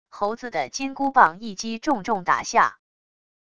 猴子的金箍棒一击重重打下wav音频